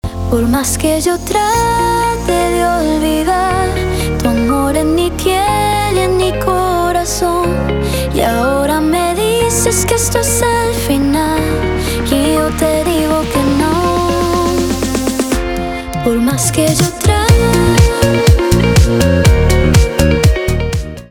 испанские , танцевальные
нарастающие , гитара , битовые , басы , качающие , кайфовые